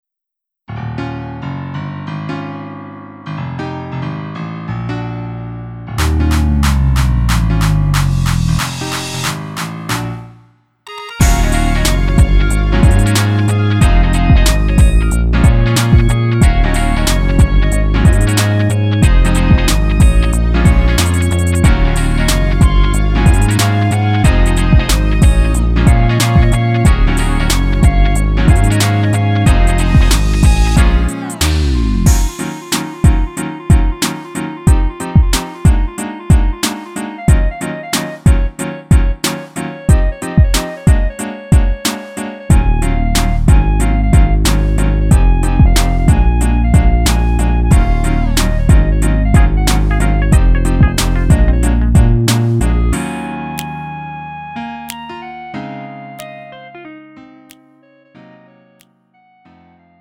음정 -1키 3:44
장르 가요 구분